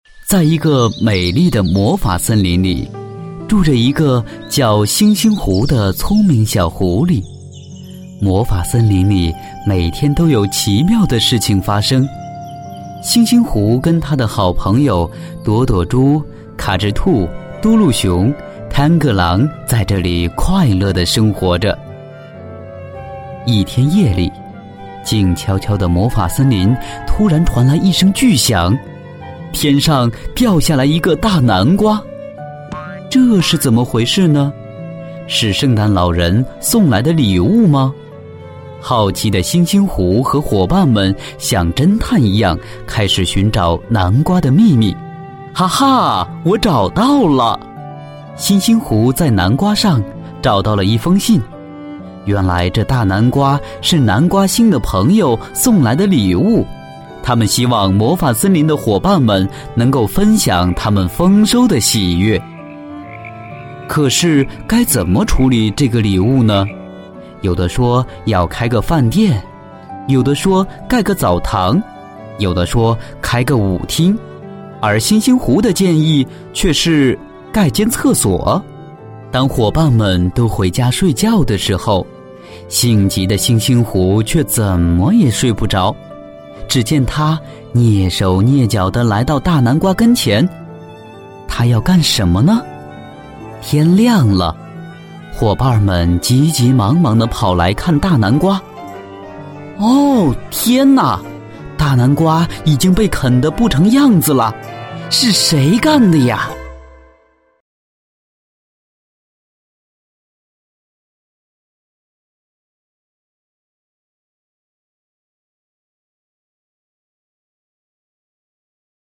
普通话一级甲等